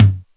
80s Digital Tom 02.wav